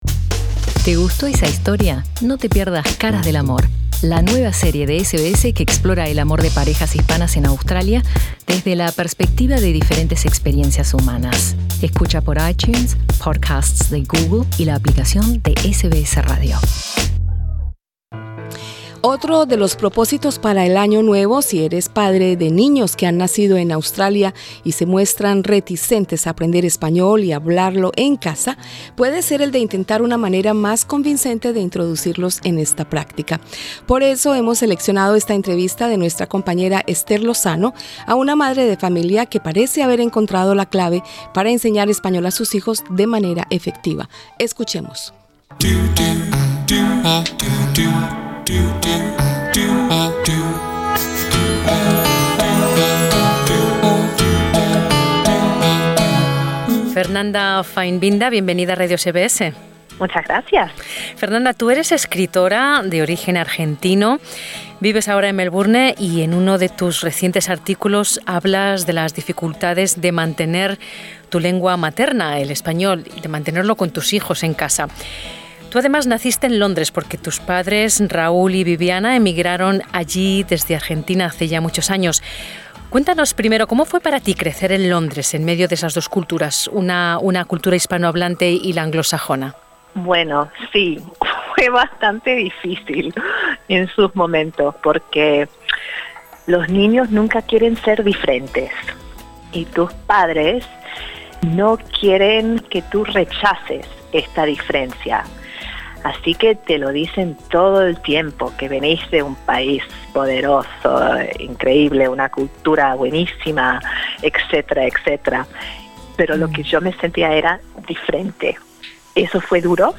Escucha aquí los consejos prácticos de una madre de familia que ha logrado introducir el segundo idioma a sus hijos desde temprana edad Otros temas en SBS español: READ MORE Bono especial para comprar casa en Australia.